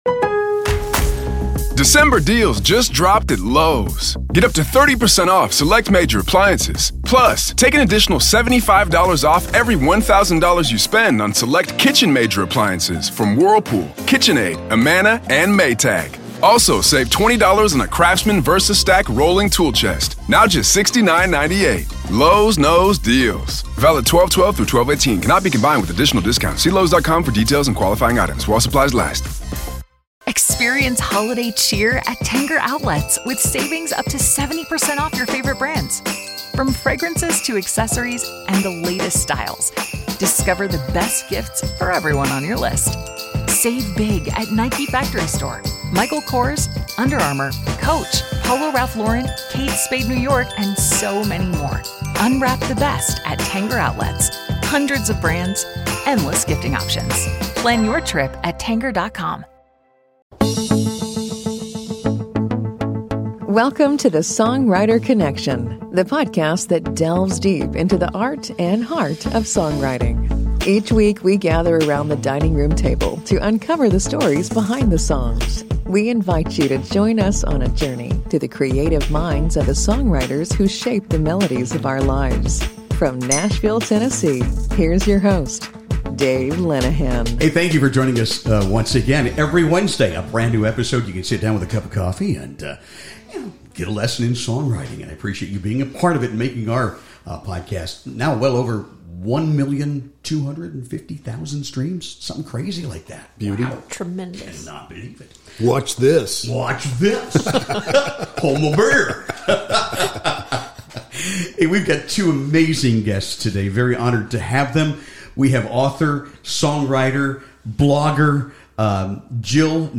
In this intimate conversation